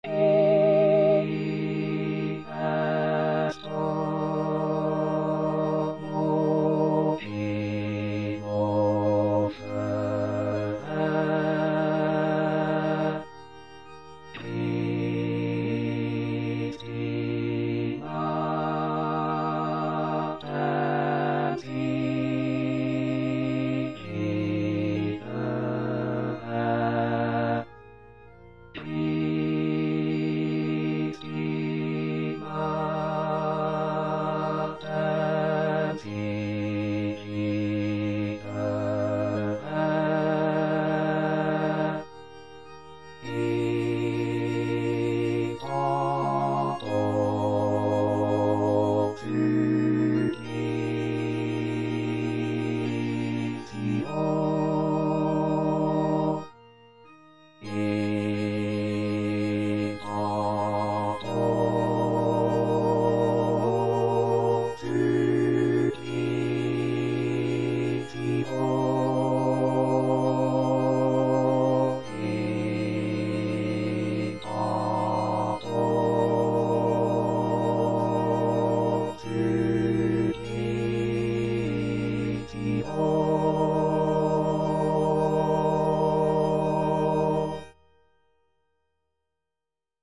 Parole 3: Mulier, ecce filius tuus        Prononciation gallicane (à la française)